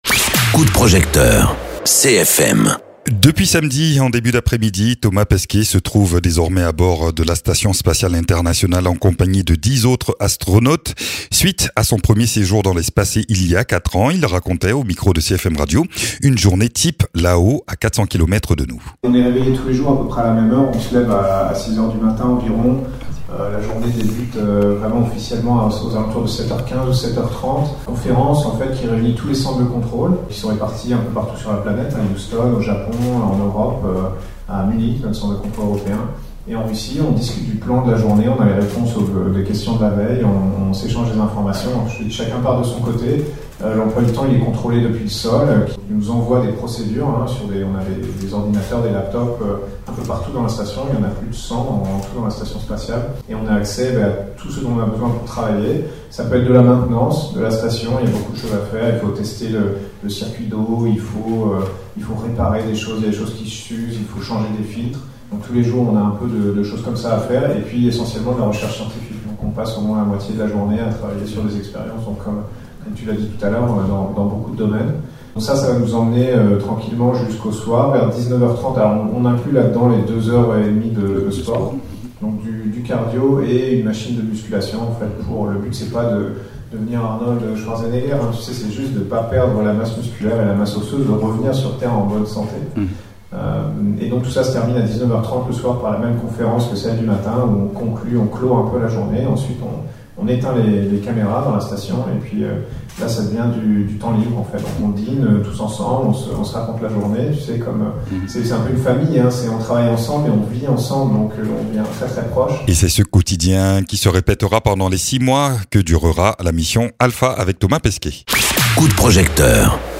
Interviews
Invité(s) : Thomas Pesquet, astronaute.